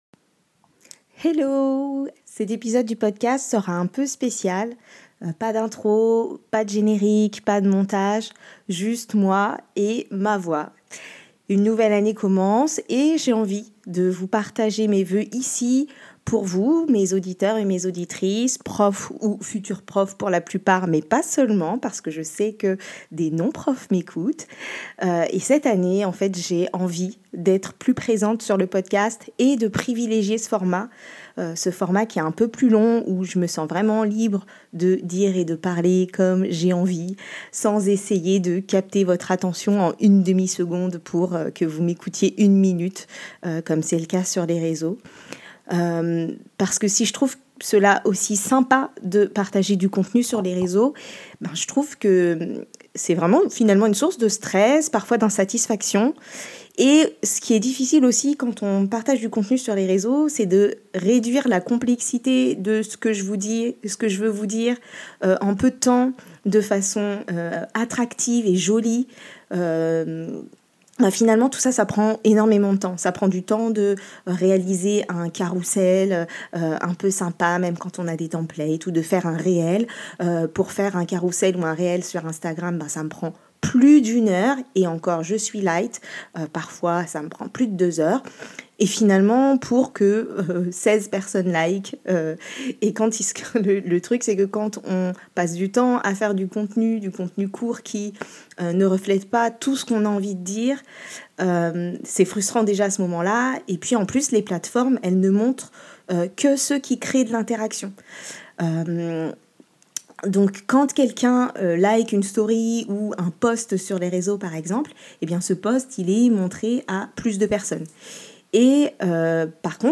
Pour cet épisode, j’ai pris le micro, tout simplement, et je vous parle sans montage, pour vous souhaiter mes 12 voeux.